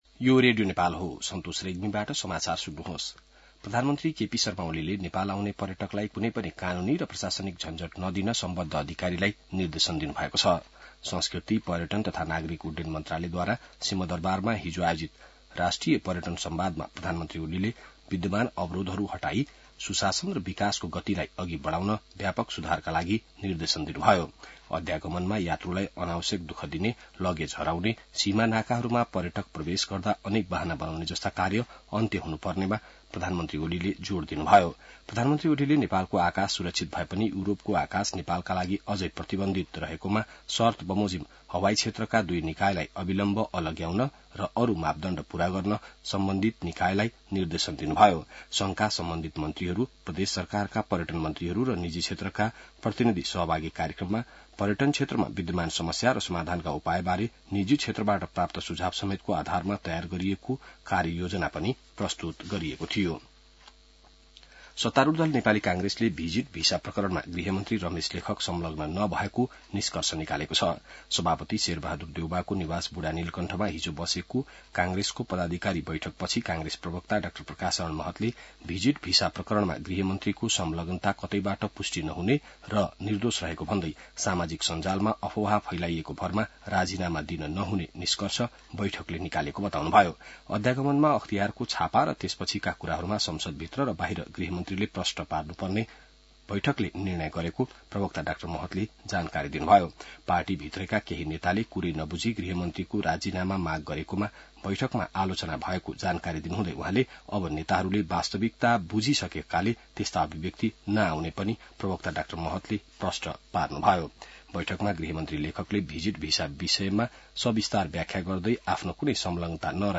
बिहान ६ बजेको नेपाली समाचार : १३ जेठ , २०८२